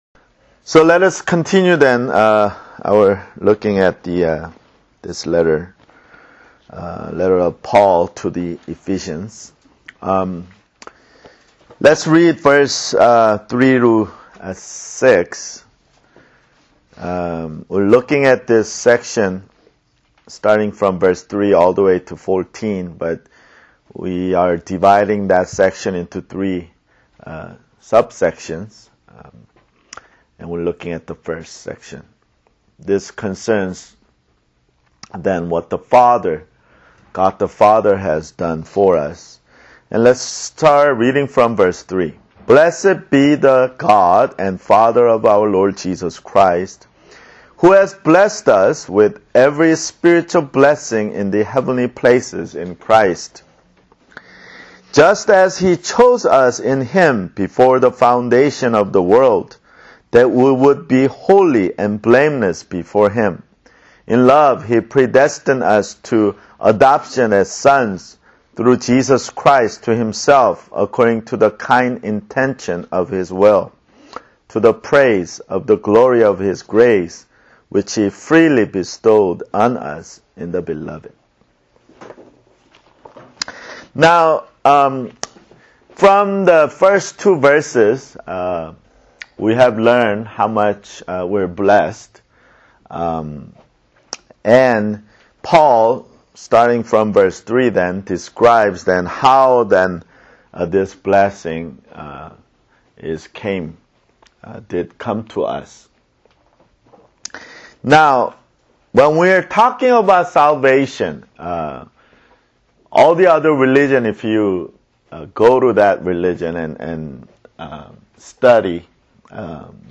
[Bible Study] Ephesians 1:3-6 (8)